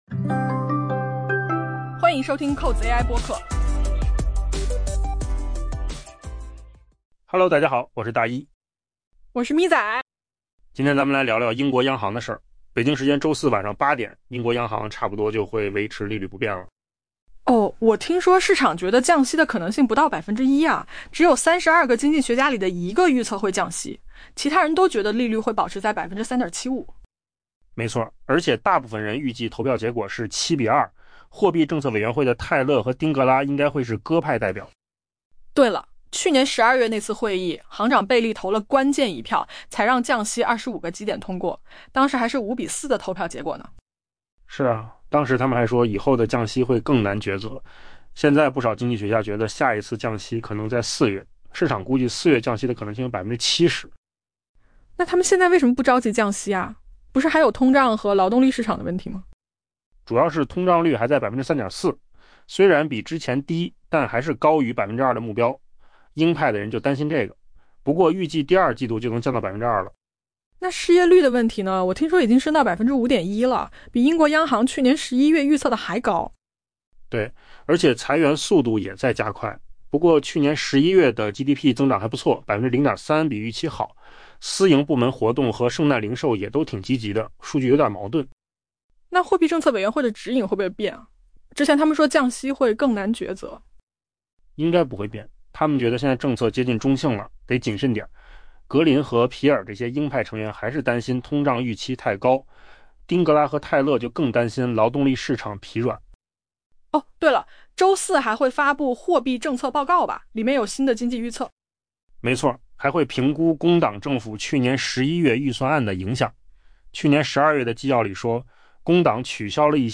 AI 播客：换个方式听新闻 下载 mp3 音频由扣子空间生成 北京时间周四晚 8 点，英国央行几乎肯定会维持利率不变。